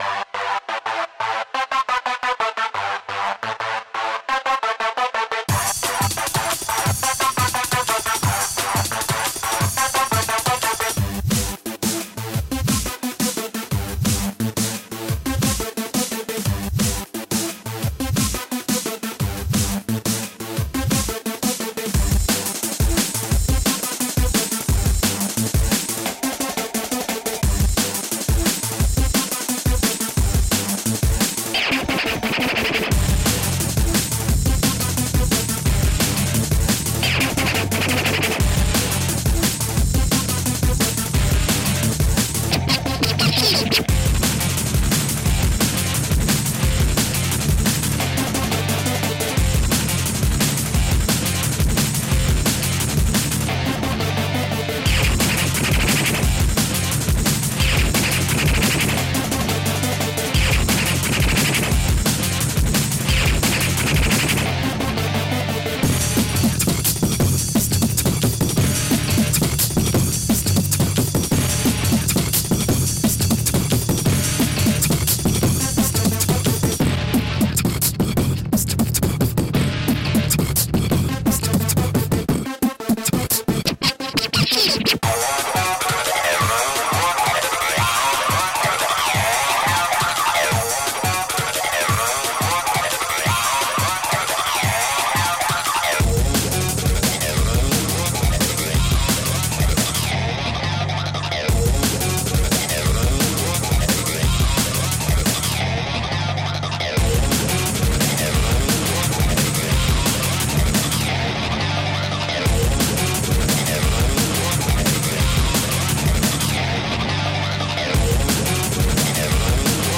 DRUM С ОБАЛДЕННЫМИ РИТМАМИ ЭЛЕКРО-ГИТАРЫ!ТАКОГО ЕЩЕ НЕ БЫЛО--ВРУБАЕМ КОЛОНКИ С САББУФЕРОМ И РУБИМСЯ! БОЛЬШЕ ТРЭША И УГАРА!